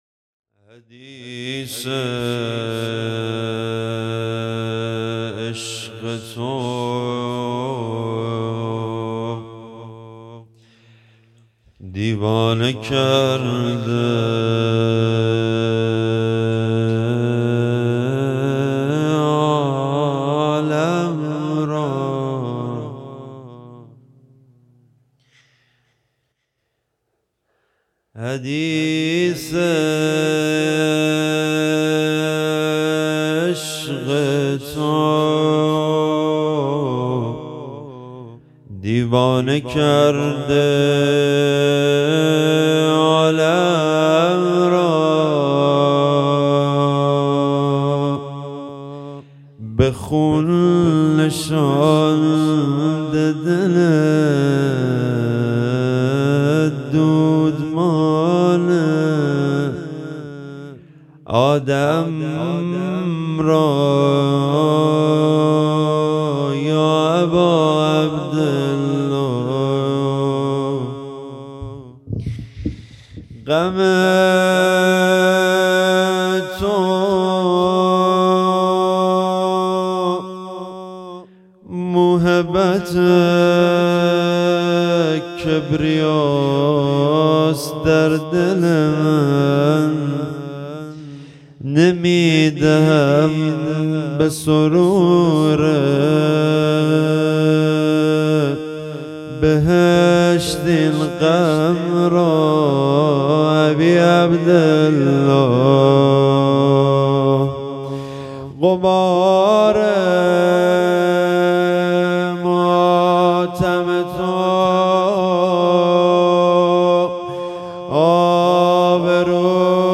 روضه
هیئت هفتگی